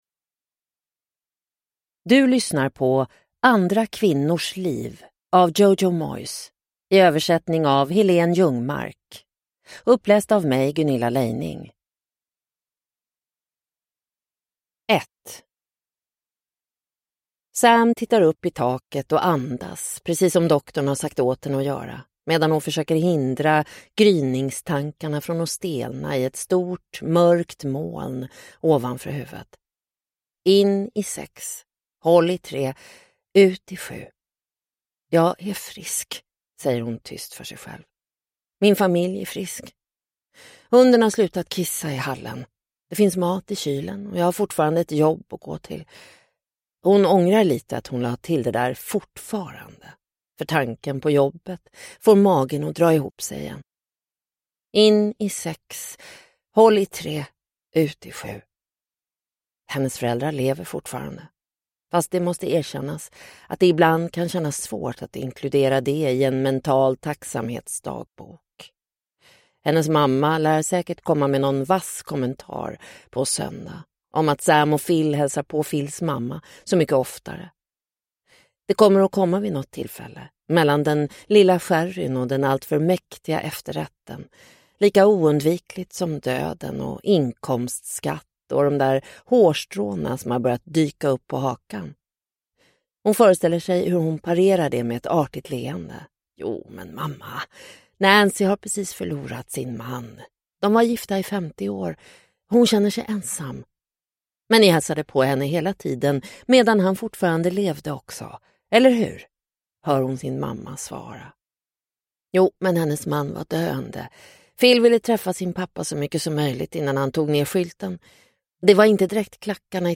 Andra kvinnors liv – Ljudbok – Laddas ner